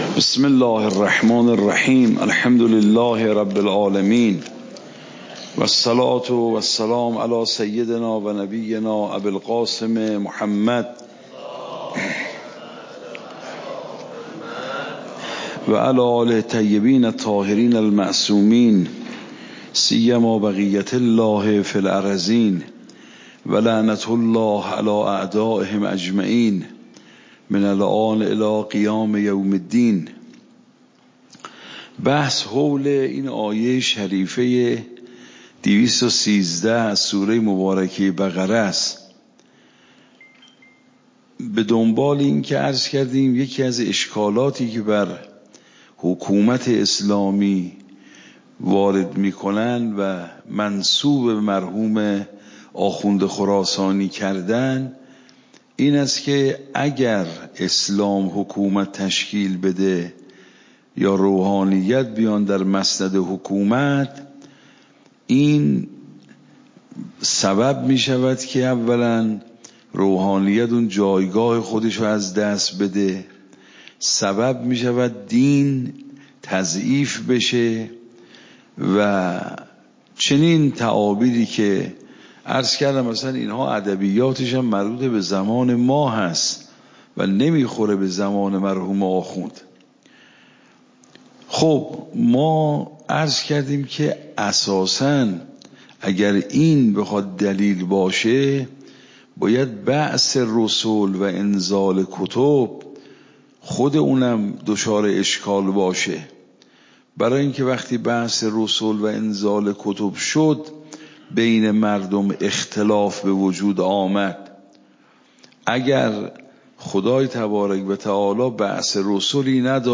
صوت درس